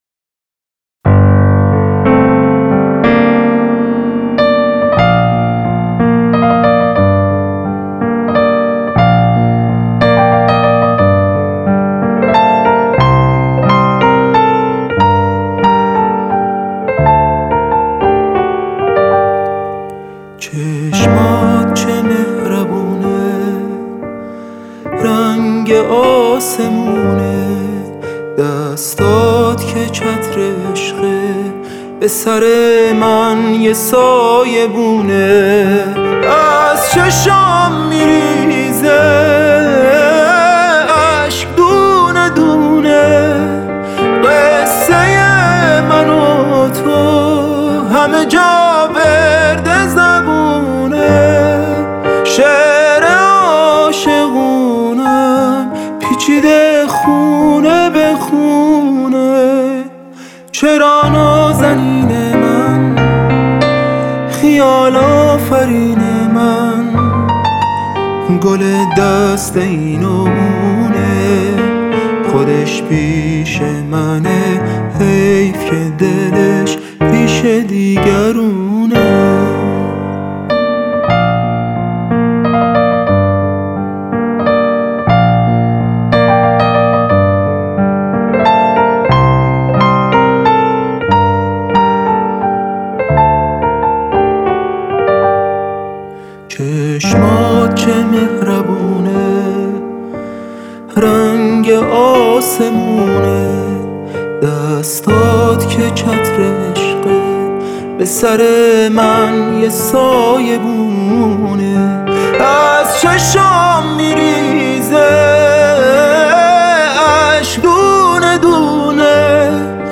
این قطعه بازخوانی یک ترانه قدیمی است.